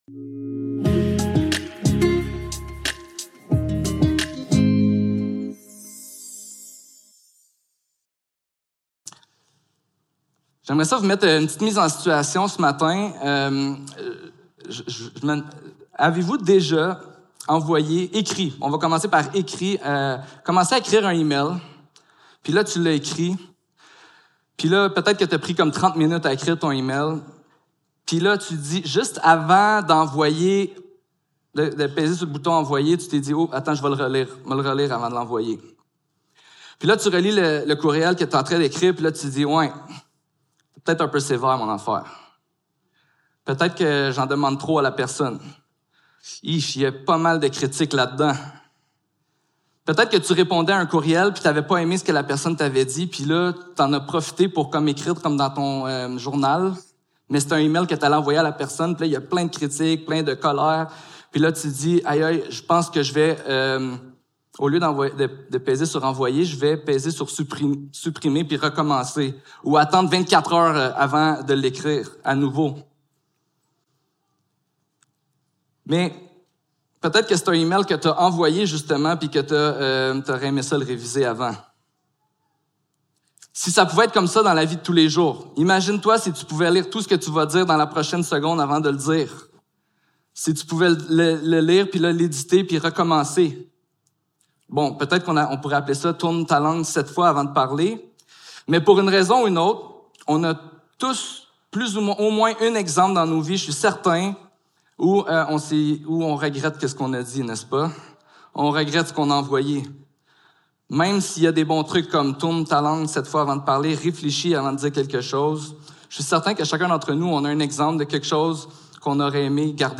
Jacques 3.1-11 Service Type: Célébration dimanche matin Vers la maturité spirituelle #6 Savais-tu que la bible dit que tu as un grand pouvoir?